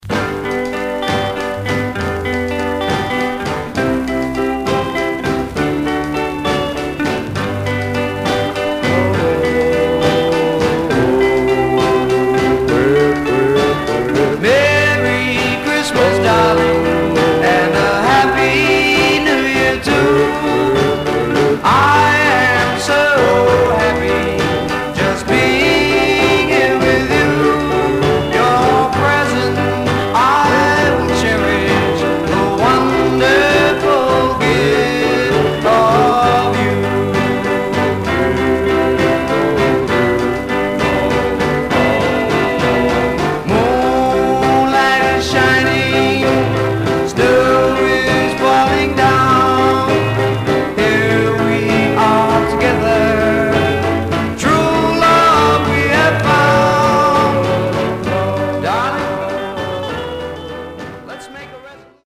Condition Surface noise/wear Stereo/mono Mono